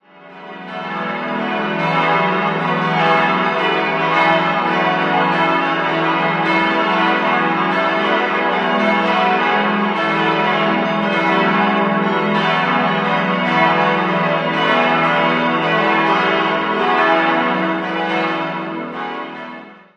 9-stimmiges Geläute: e'-fis'-gis'-h'-cis''-fis''-ais''-cis'''-dis''' Alle Glocken stammen aus der Gießerei Friedrich Wilhelm Schilling aus Heidelberg.